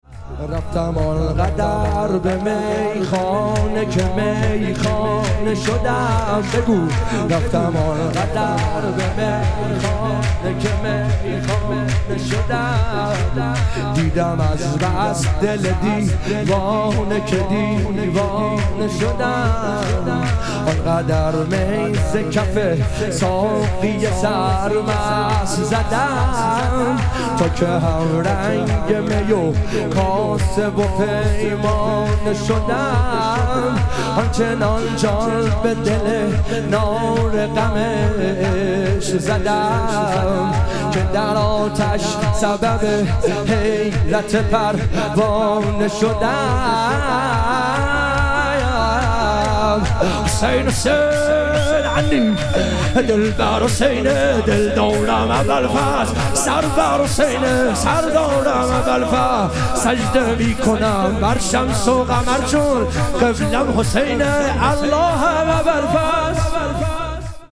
ظهوروجود مقدس امام هادی علیه السلام - شور